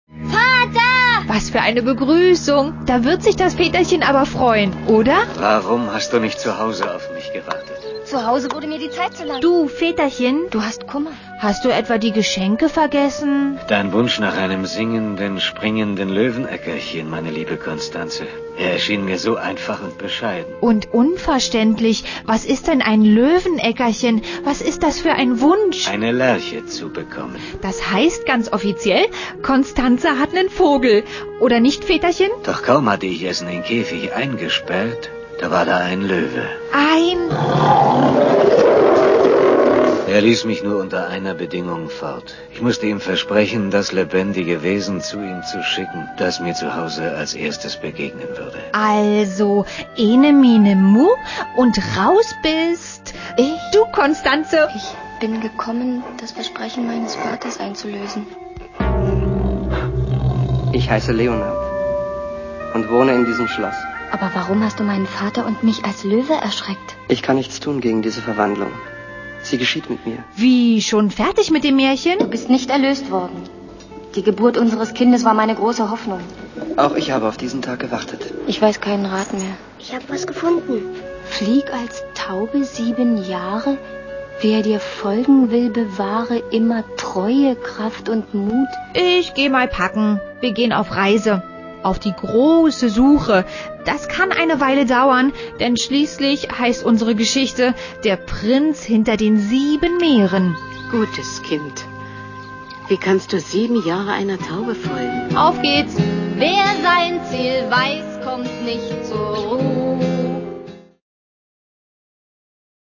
Audio-Trailer